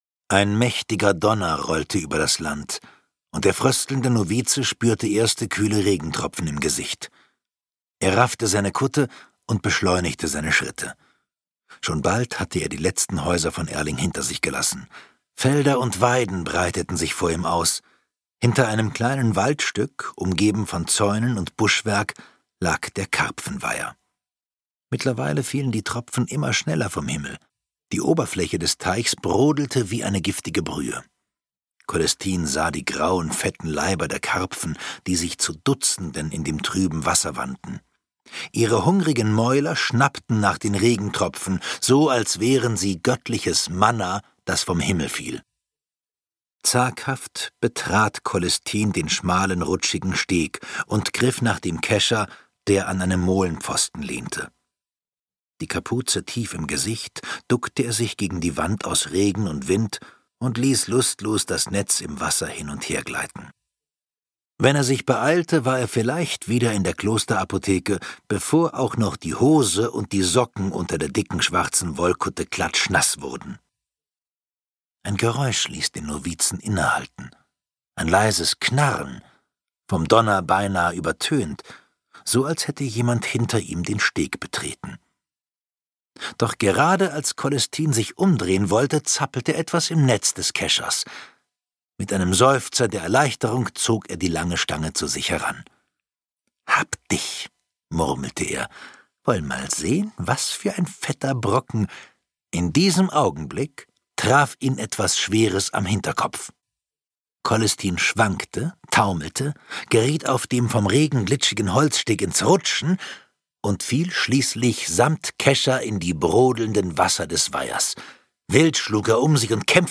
Der Hexer und die Henkerstochter (Die Henkerstochter-Saga 4) - Oliver Pötzsch - Hörbuch